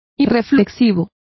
Complete with pronunciation of the translation of unreflecting.